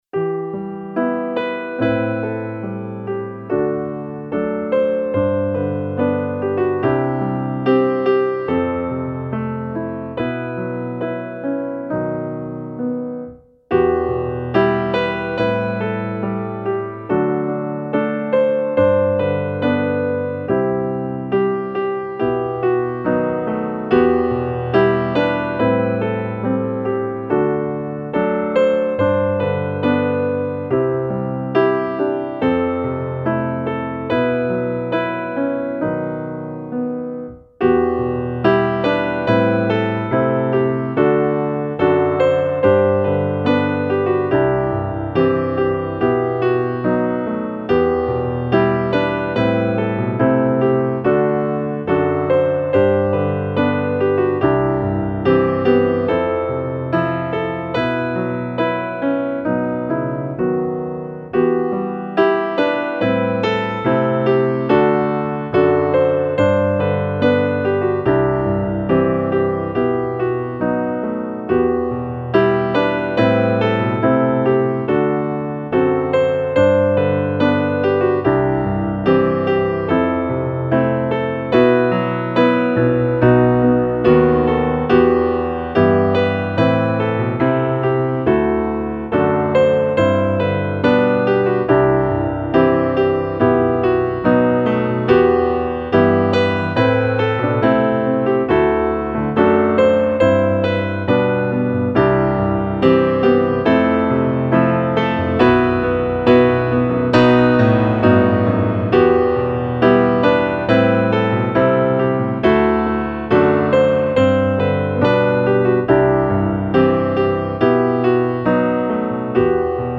musikbakgrund